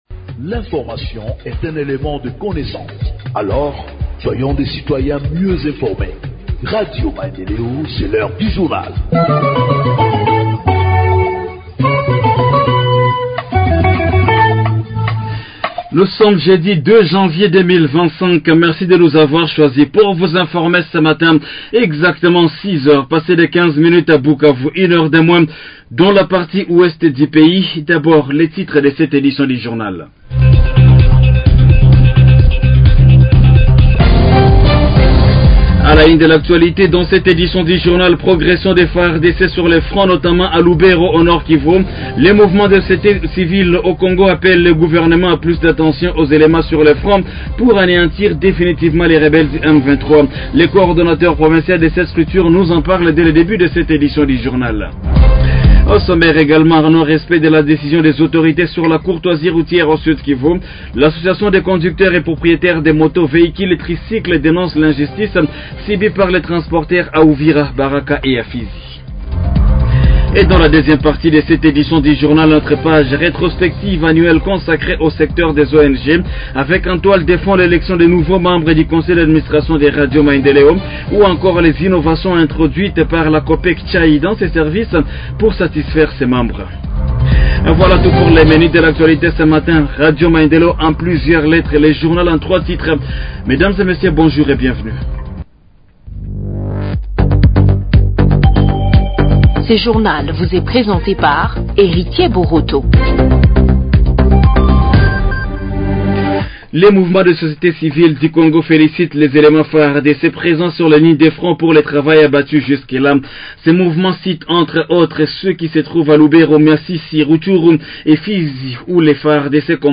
Journal français du 02 janvier 2025 – Radio Maendeleo